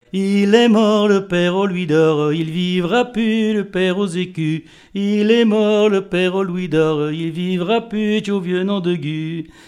Couplets à danser
branle : avant-deux
Pièce musicale inédite